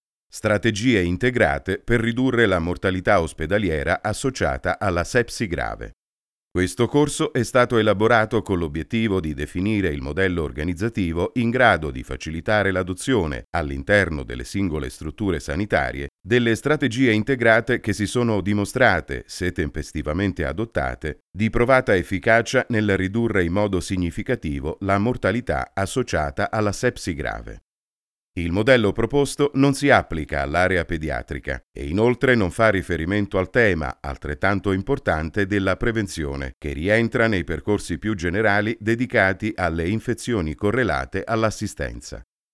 Lavoro anche in home studio (microfono Neumann Tlm 49, interfaccia Motu UltraLite-MK3 Hybrid, ambiente insonorizzato).
Sprechprobe: eLearning (Muttersprache):